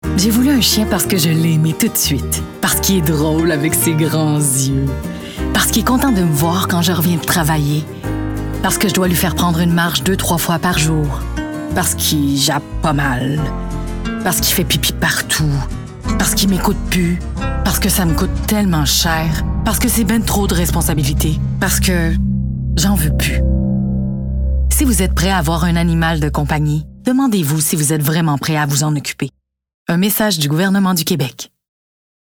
Publicity - Démo voix complet Publicity - Voix annonceur 1 Publicity - Voix annonceur 2 Publicity - Voix annonceur 3 Publicity - Person./Ann. 4 Publicity - Voix Person./Ann 5 Publicity - Voix annonceur 6